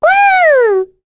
Rephy-Vox_Happy7.wav